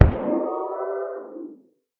guardian_death.ogg